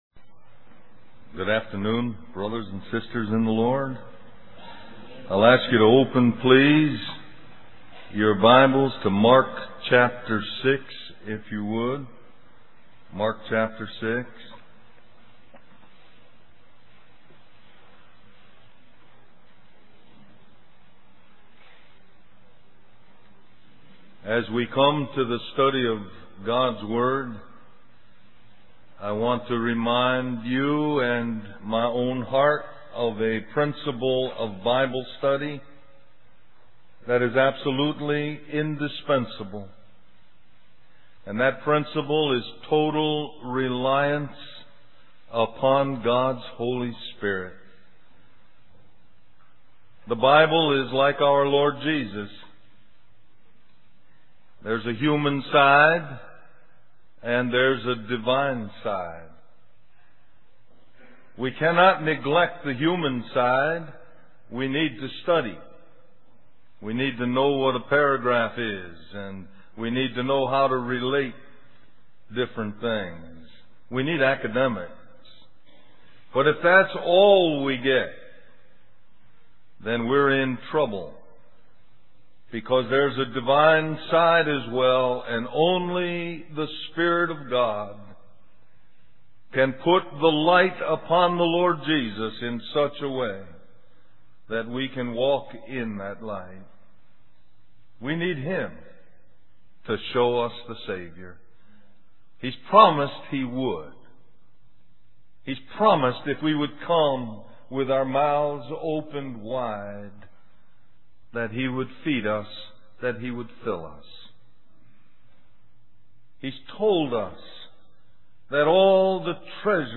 Christian Family Conference